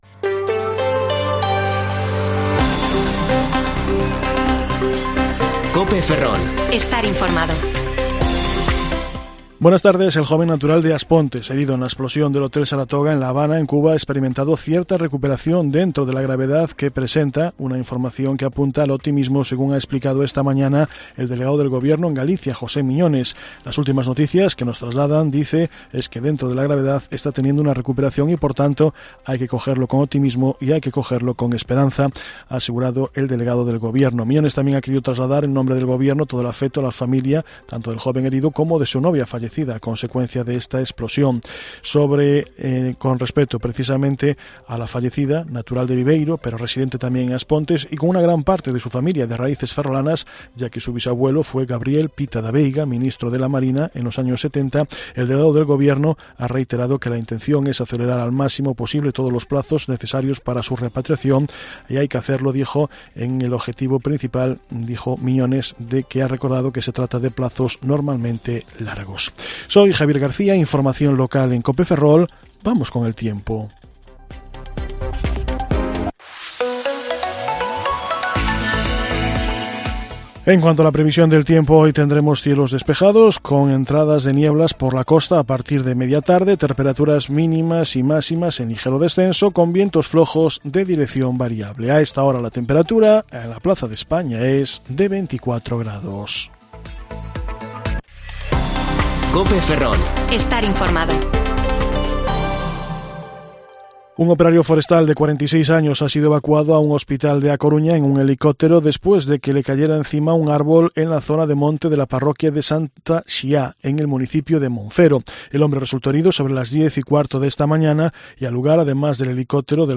Informativo Mediodía COPE Ferrol 9/5/2022 (De 14,20 a 14,30 horas)